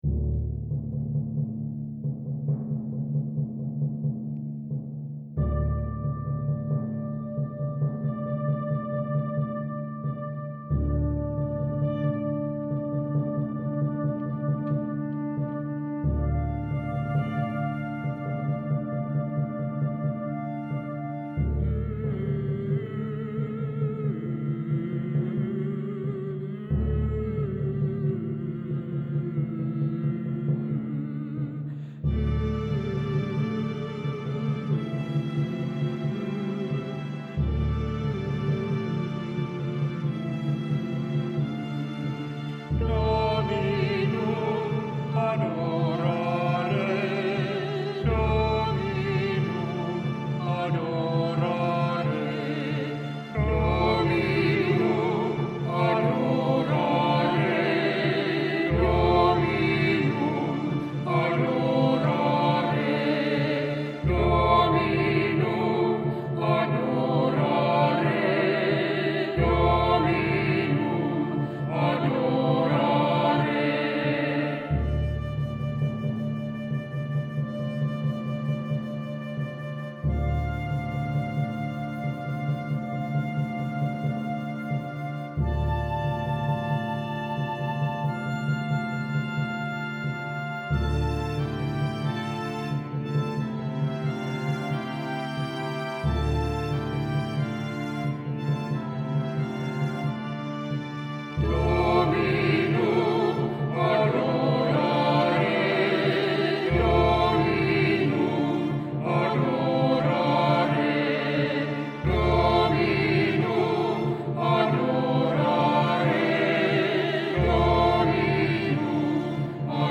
3-osainen sinfoninen teos